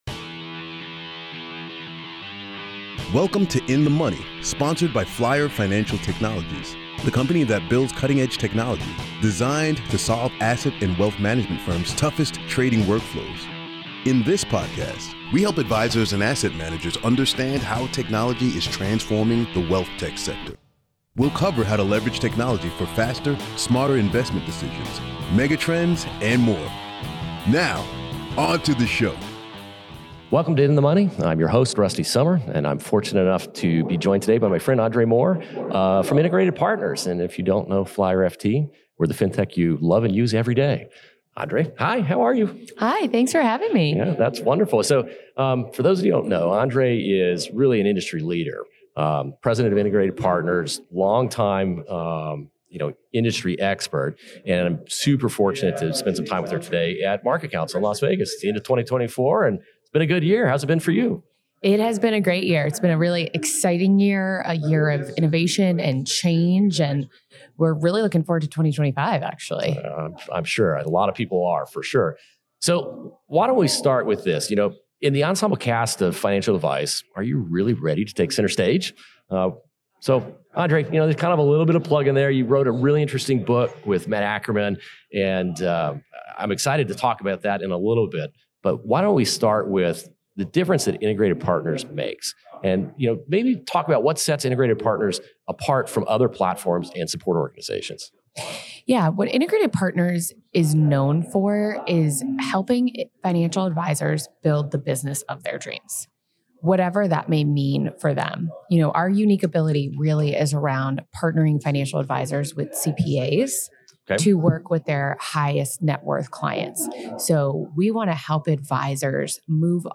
As the conversation unfolds, you’ll learn about their innovative approach to advisor support, including leveraging AI for strategic planning and the power of community building.